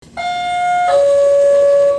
Sound Effects
Doorbell Pa System 2